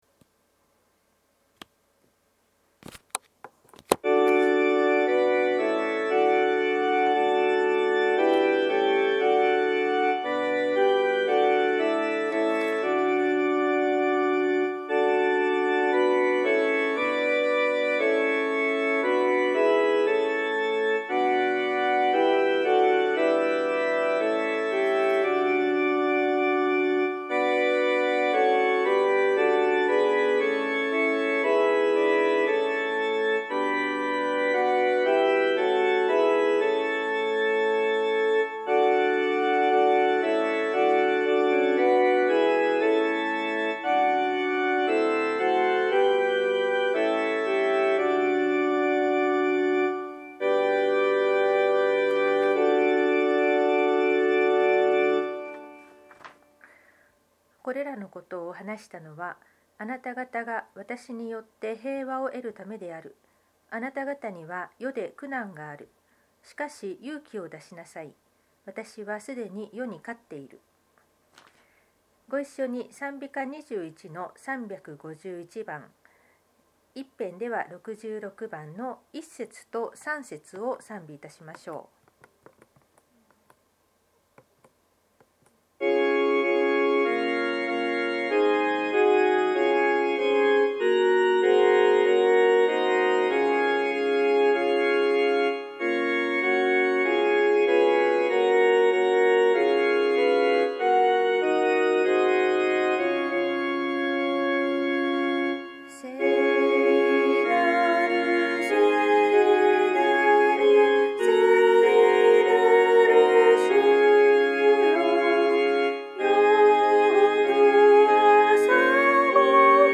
聖日礼拝のご案内（聖霊降臨節第２主日） – 日本基督教団 花小金井教会
左下の三角形のアイコンをクリックすることにより礼拝の音声を聞くことができます。